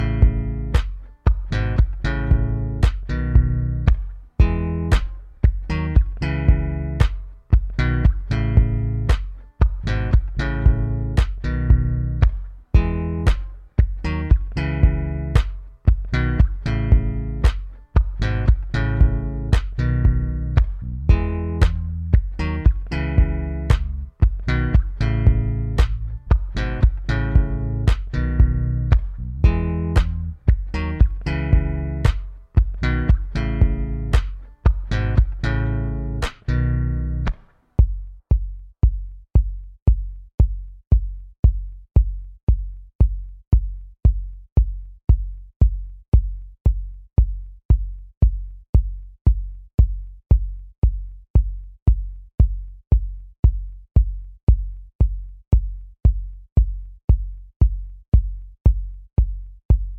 Minus Main Guitar For Guitarists 3:57 Buy £1.50